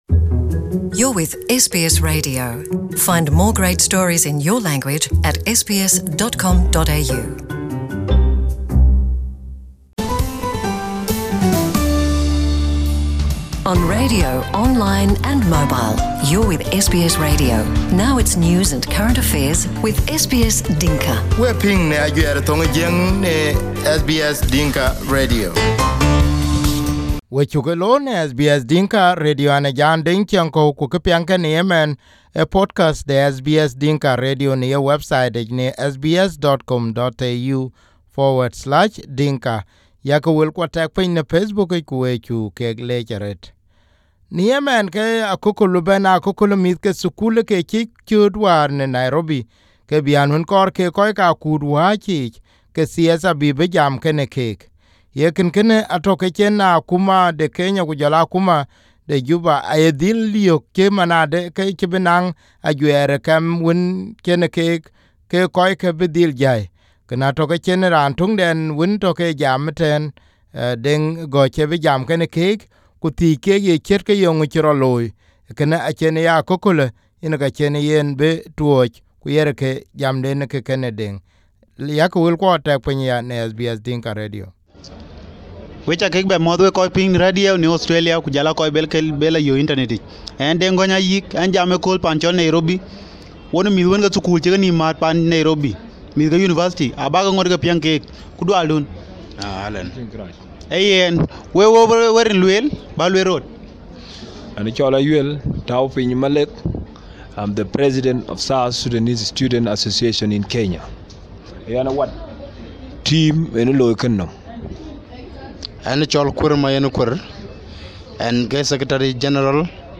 On 12/May/2018, South Sudanese students in Kenya have the event they planned to be attended by the members of South Sudanese canceled. In the first their first interview with SBS Dinka, the student’s leaders expressed their dismay on how they were reported to the Kenyan police despite having followed all avenues.